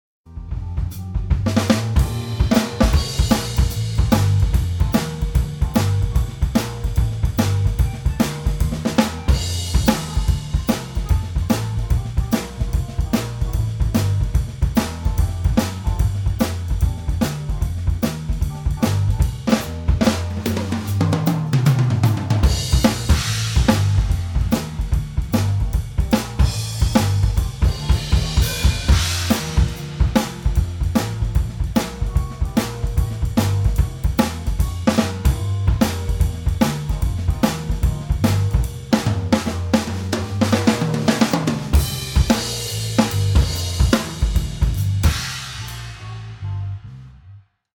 Live-Mitschnitt bei Kneipengig - Bericht mit Audiobeispielen
Mischung (nur Drums)
Ich würde beim nächsten Mal das 14er Tom unten in Floor-Position getrennt abnehmen - es geht auf den Overheads leider etwas unter... aber sonst passte alles für den Aufwand ganz gut vom Sound.
Mix_Drums-only.MP3